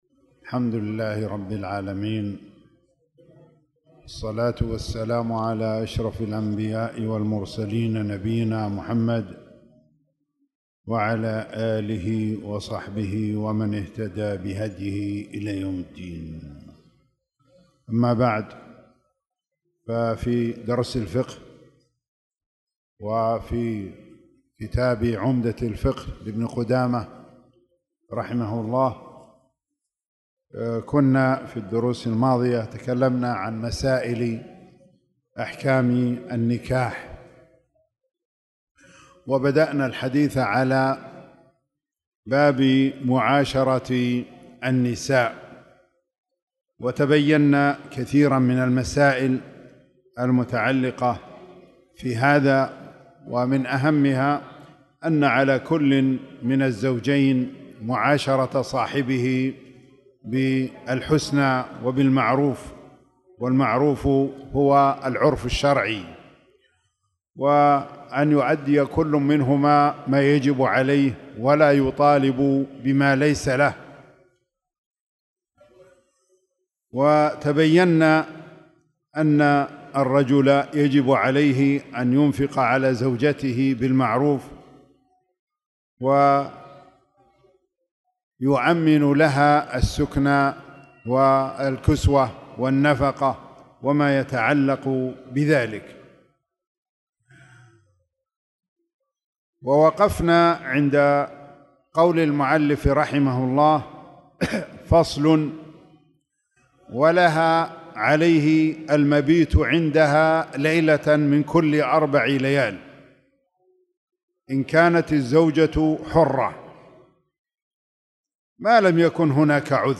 تاريخ النشر ٣٠ ربيع الثاني ١٤٣٨ هـ المكان: المسجد الحرام الشيخ